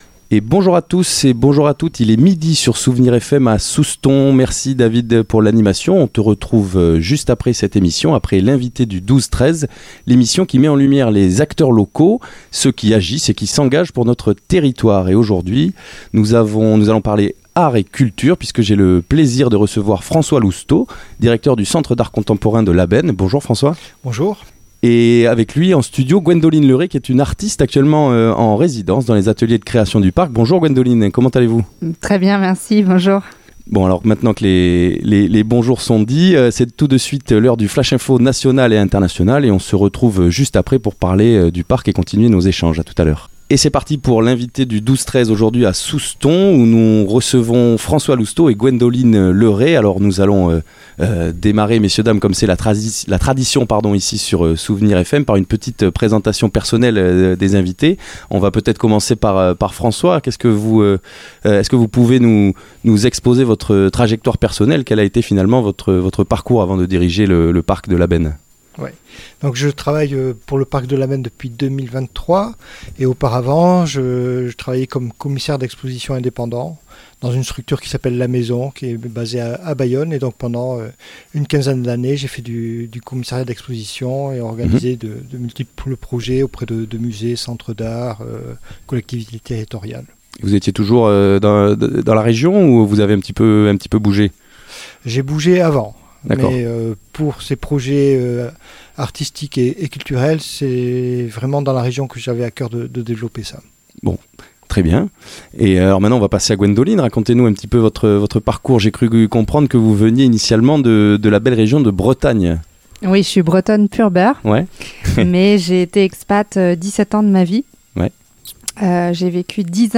L'entretien a mis en lumière la mission essentielle du PARCC : offrir un espace de recherche et de liberté aux créateurs tout en créant un pont direct avec le public.